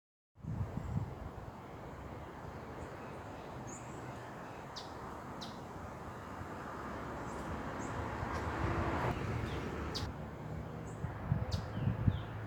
Ruby-crowned Tanager (Tachyphonus coronatus)
Province / Department: Rio Grande do Sul
Location or protected area: Gramado
Condition: Wild
Certainty: Photographed, Recorded vocal